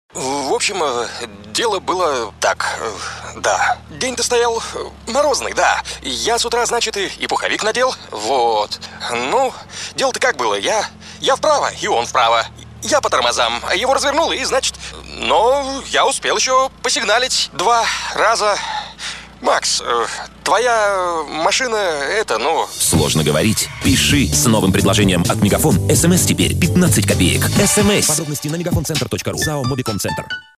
В радиорекламе герои пытаются признаться так, когда говорят «трудно подобрать слова» — попытка объяснения в любви и нелегкий рассказ другу о том, как его машина превратилась в руины.
Звук: студия Rec’s production.